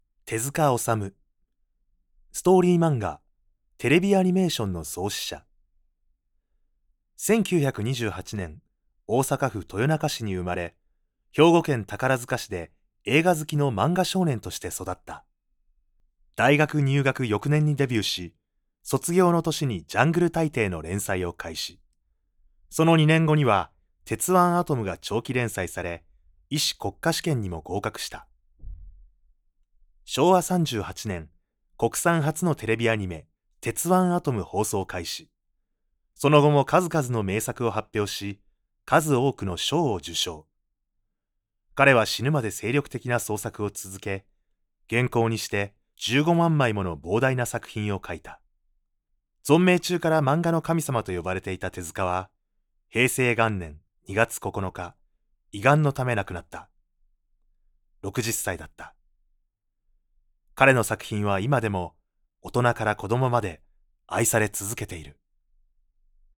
I can adapt my voice to your needs, from a calm tone to a bouncy, energetic voice.
Can speak Kansai dialect.
e-learning
Settled